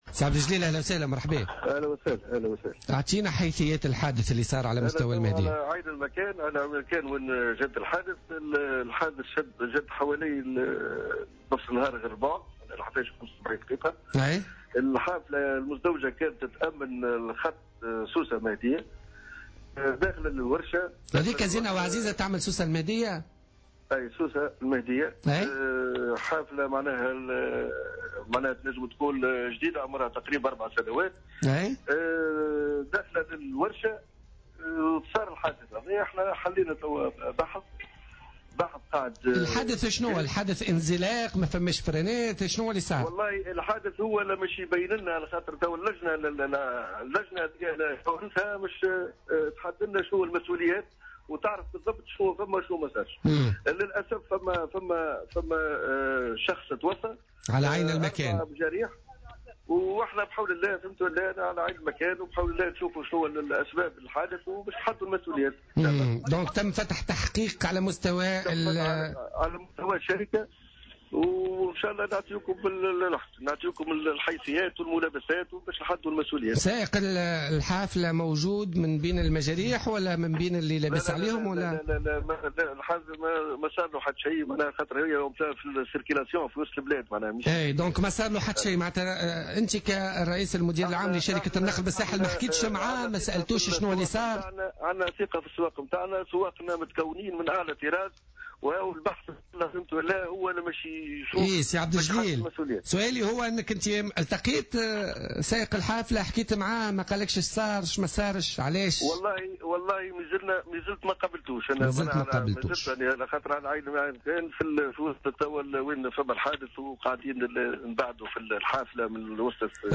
وأضاف في مداخلة له اليوم في برنامج "بوليتيكا" أن الحافلة التي اصطدمت بالمقهى، مما أسفر عن وفاة شخص وإصابة 4 آخرين، هي حافلة مزدوجة و تؤمن السفرات على الخط الرابط بين سوسة و المهدية.